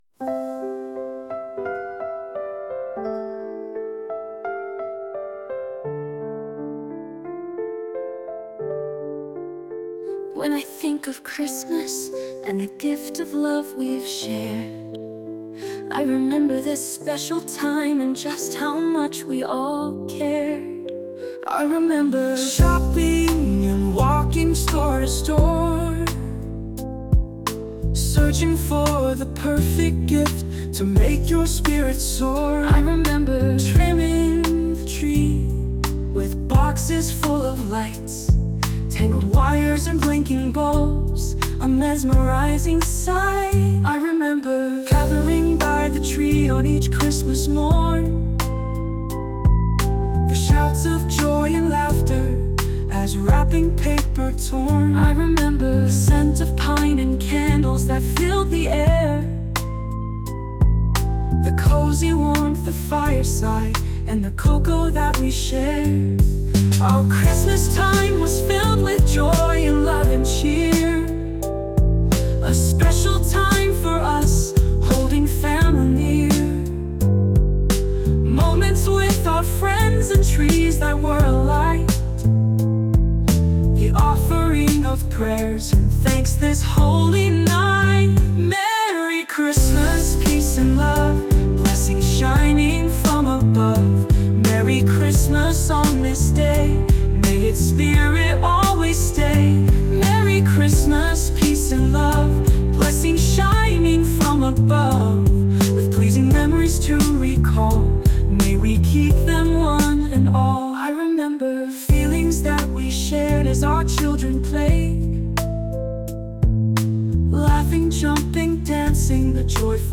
A nostalgic song of love and rememberence.